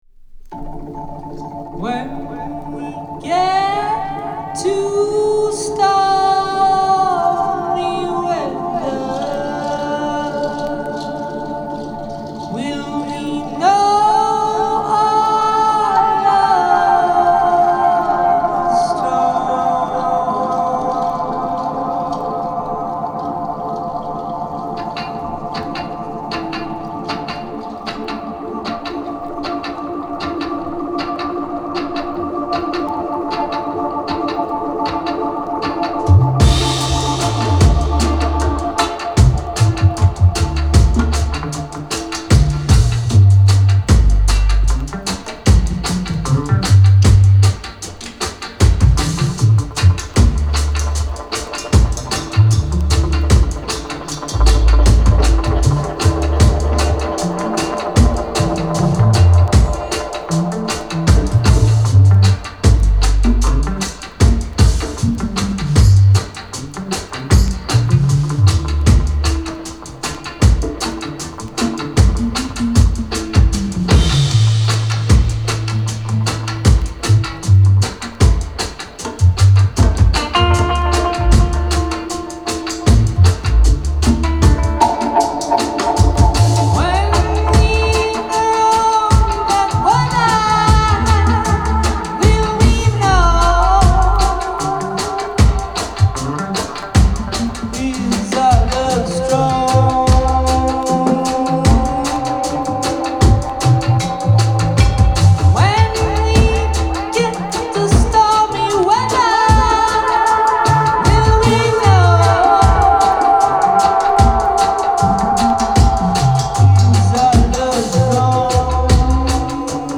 … a true live dub session in a brooklyn garage space …